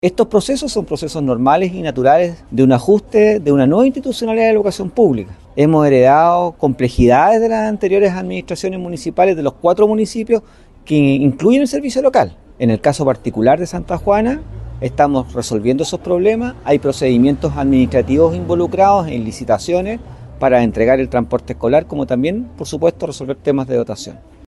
Ante los cuestionamientos e inquietudes de las familias y la Municipalidad, el director ejecutivo SLEP Andalién Costa, Ramón Jara, se reunió con ellos en la escuela de Chacayal, asegurando que es parte del inicio de una nueva administración.